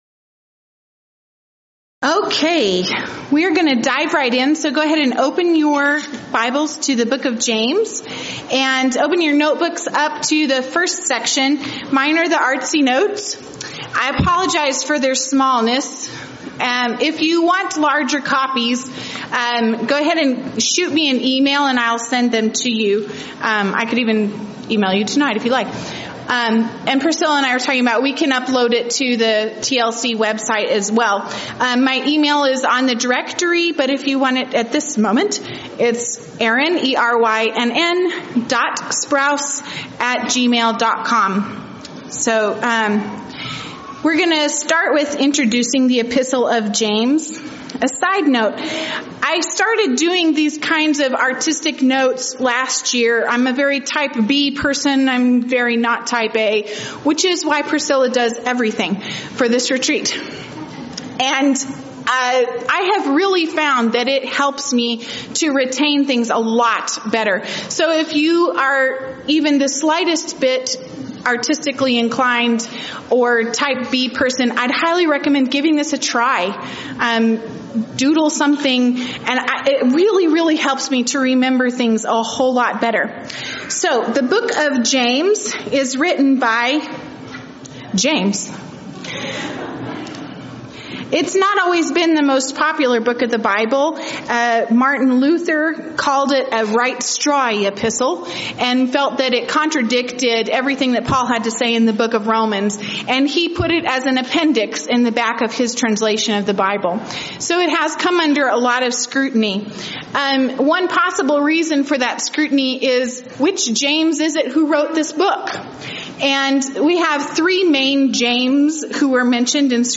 Event: 5th Annual Texas Ladies In Christ Retreat
Ladies Sessions